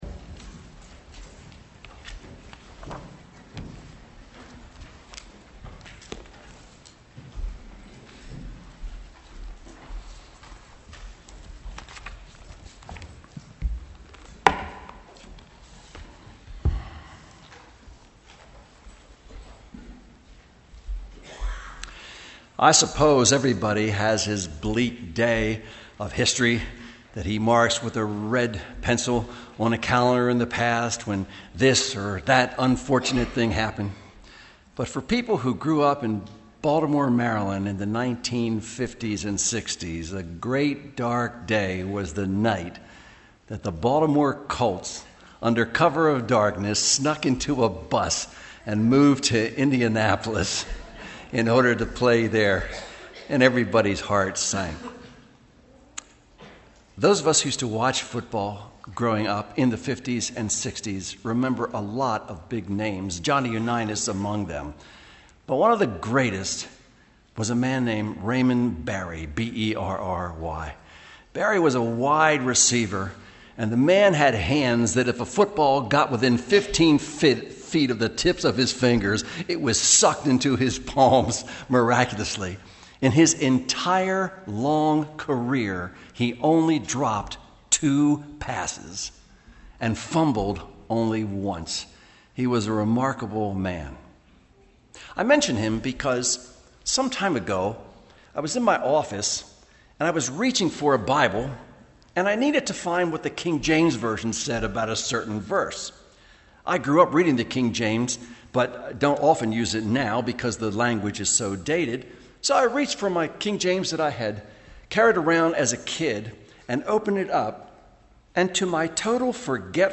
MP3 audio sermons from Brick Lane Community Church in Elverson, Pennsylvania.